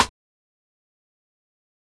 Snare (808MAFIA!!!!) (1).wav